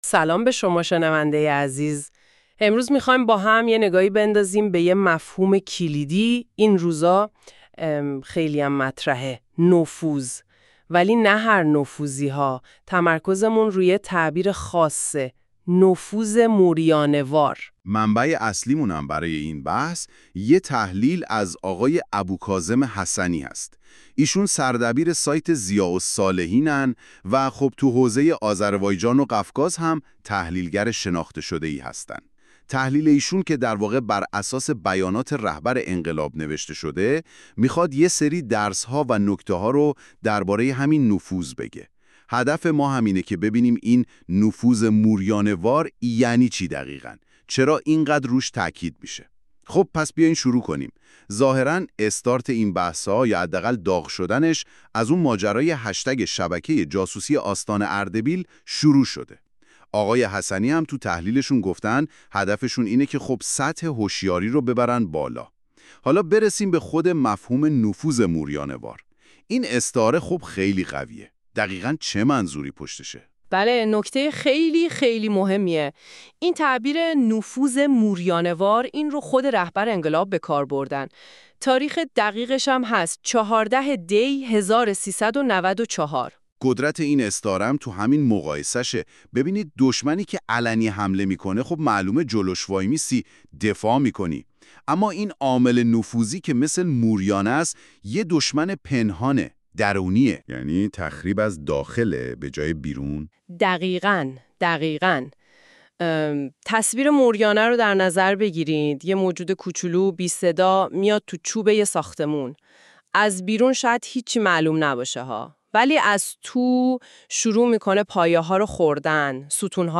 💡 این برنامه با هوش مصنوعی تهیه شده و پیشاپیش به‌خاطر برخی اشتباهات اعرابی در تلفظ‌ها عذرخواهی می‌کنیم.